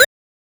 edm-perc-28.wav